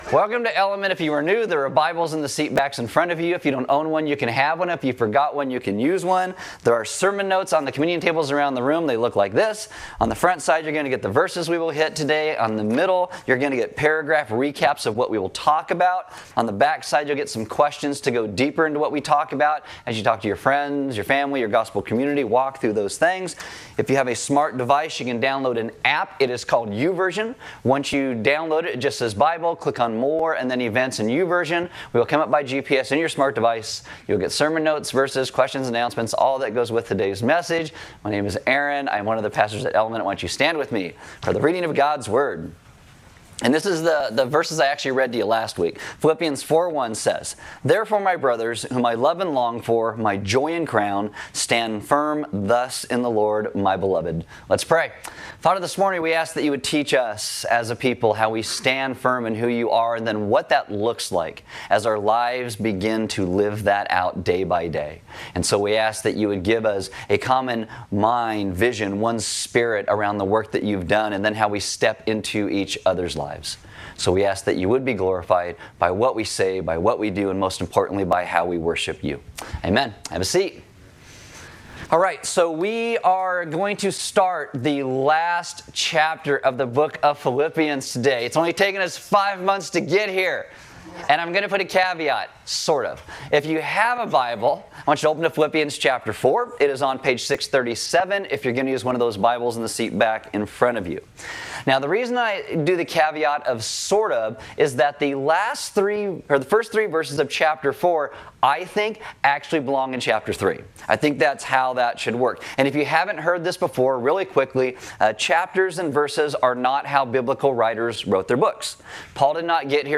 A message from the series "John." We look at Jesus' resurrection.